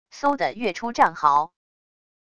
嗖的跃出战壕wav音频